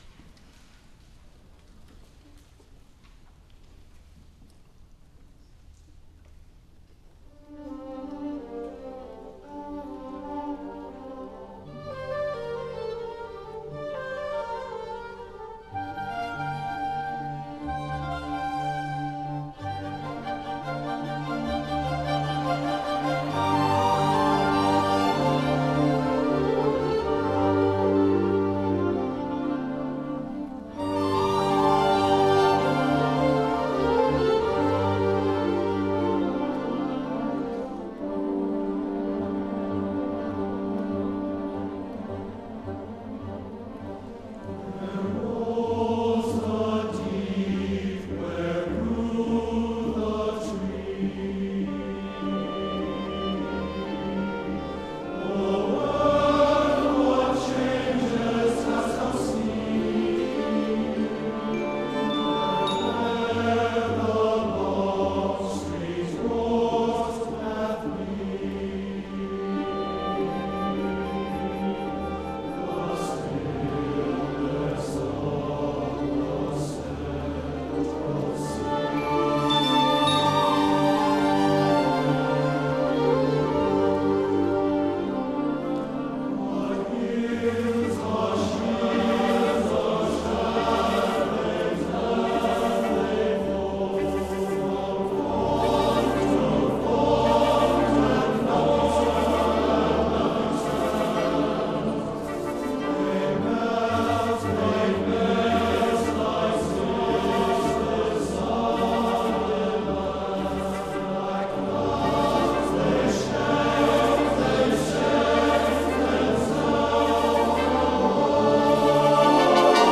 for TTBB Chorus and Piano (2003)